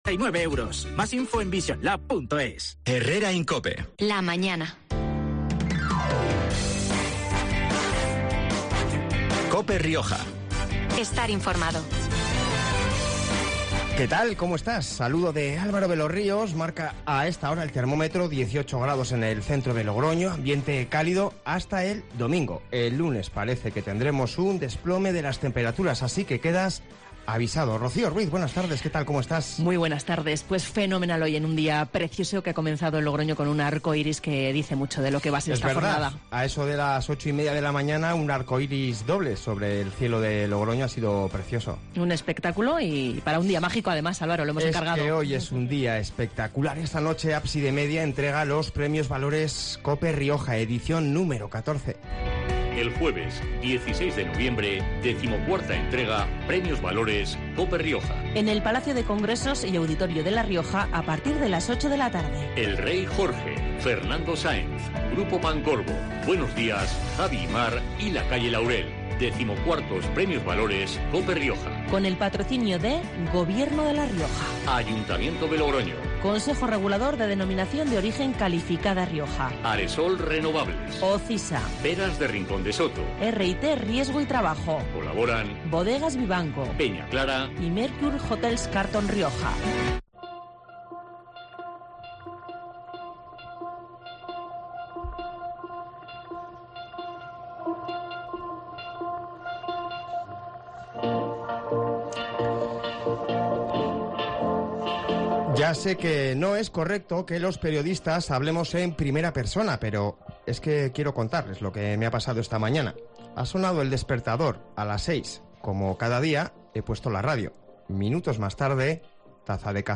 La Gala se celebraba este jueves a las 20:00 horas en la sala de Cámara del Palacio de Congresos y Auditorio de La Rioja 'Riojaforum'